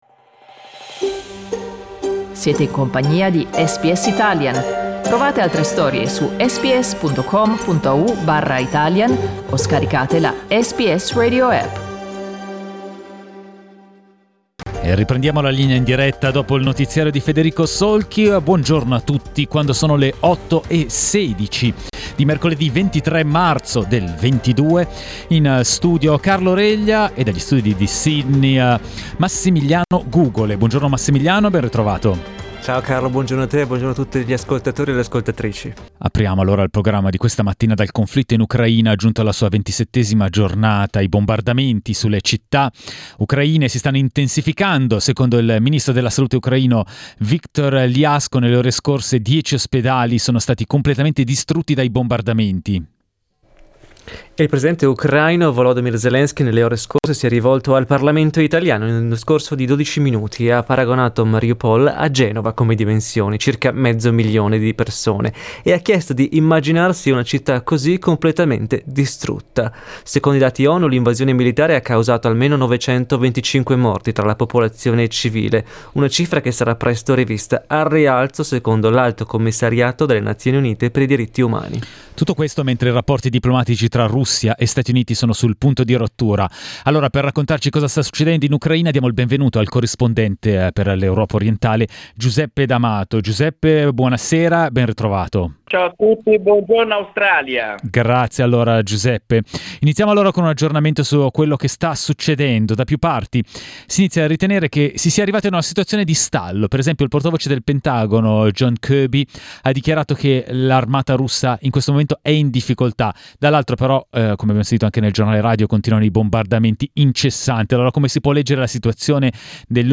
dichiara il corrispondente dall'Europa orientale